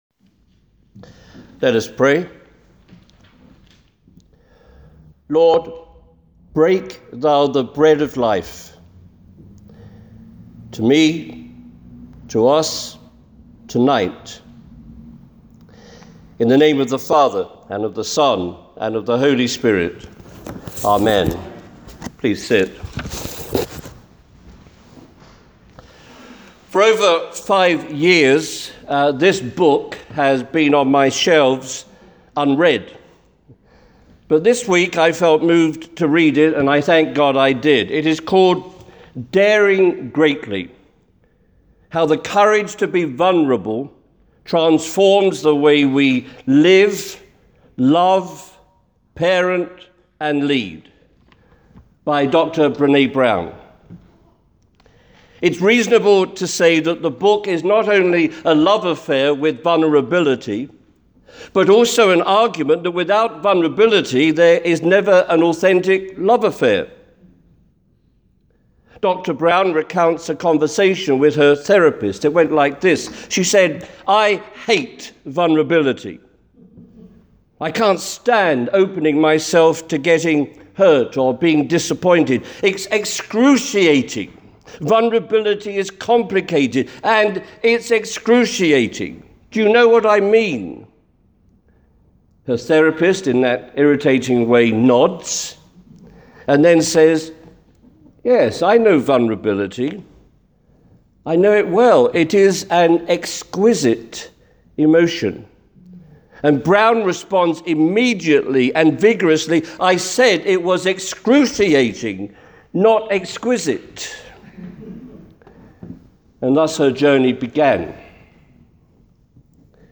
The sermon audio is below the text. There are a couple of significant additions in the spoken sermon.